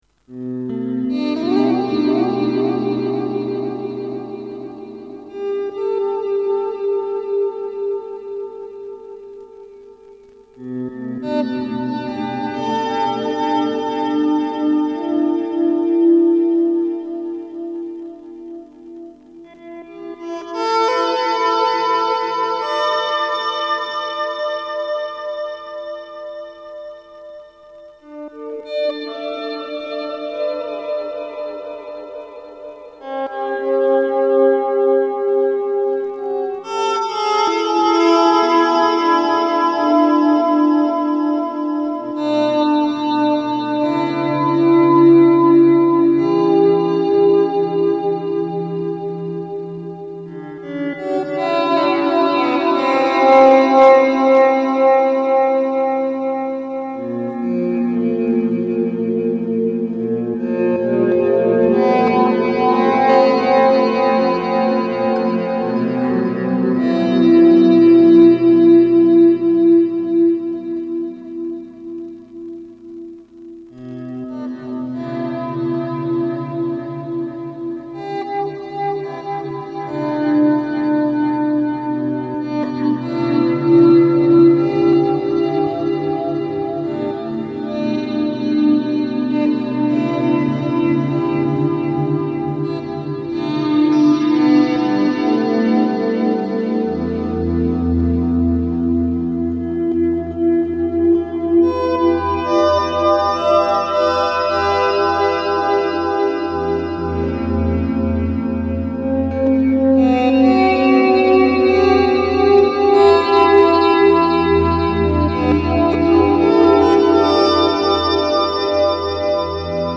Improvisation für Solo-Gitarre